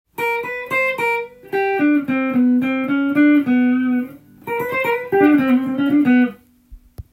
フレーズすべてがコードAm7上で使えるものになっています。
Am７の代理コードであるCmajor7のコードトーンを
弾きながらクロマチックスケールを使ったものです。